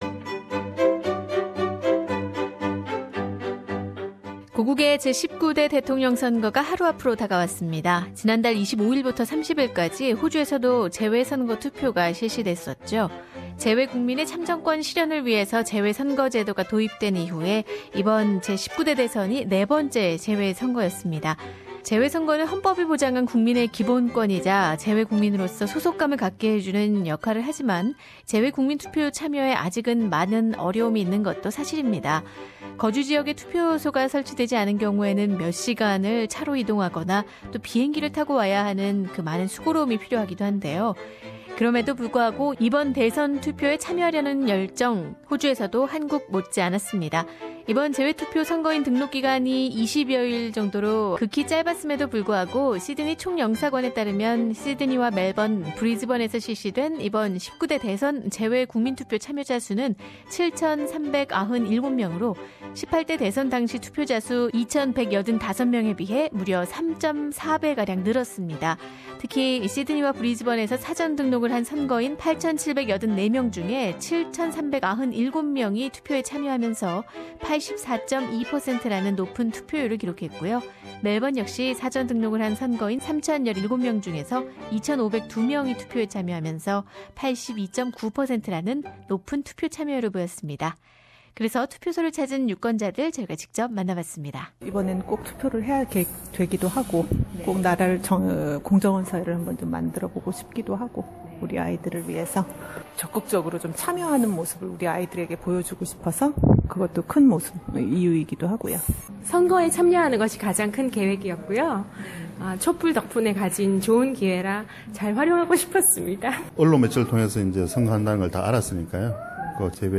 고국의 제 19대 대통령 선거가 하루 앞으로 다가왔다. 지난 달 25일부터 30일까지 호주에서 실시된 재외선거투표 현장에서 유권자들의 목소리를 들어본다.
Overseas voting for South Korean Presidential election, Melbourne polling stations Source: SBS Korean